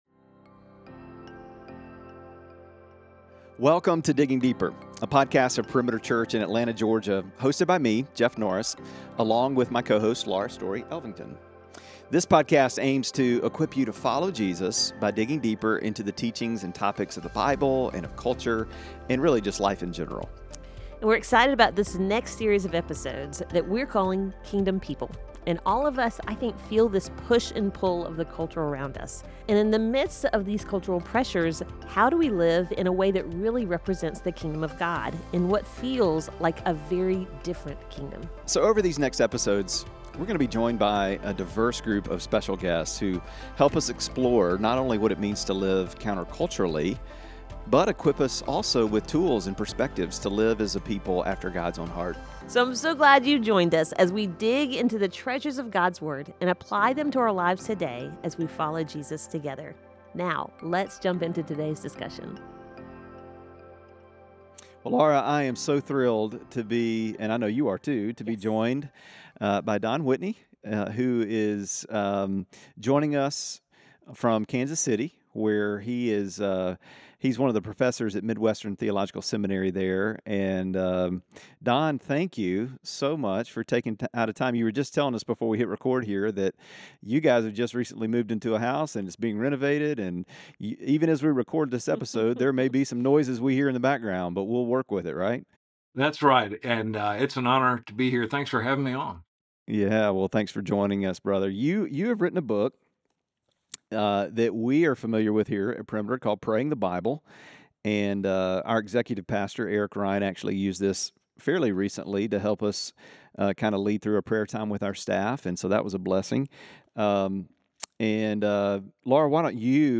The three discuss common struggles Christians face in prayer and the transformative practice of praying through Scripture, particularly the Psalms. The conversation also touches on how to hear God through His Word and the importance of praying in alignment with God’s will, especially in the context of cultural moments like elections.